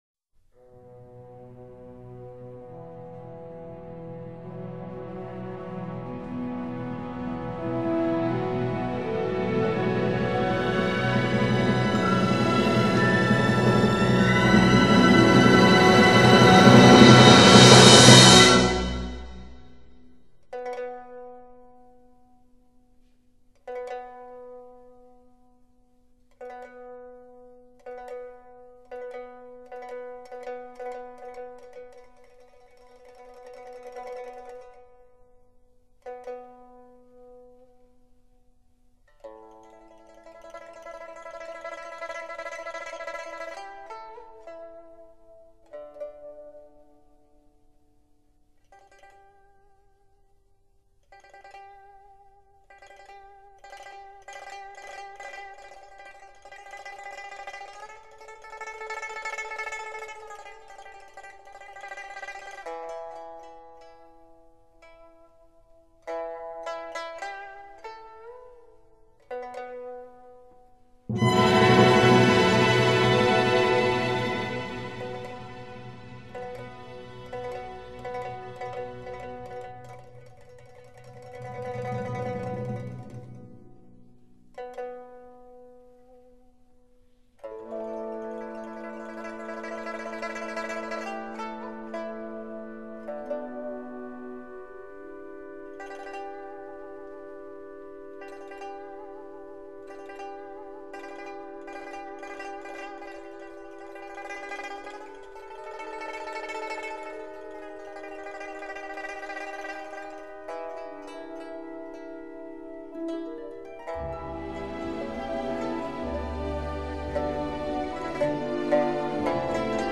琵琶协奏曲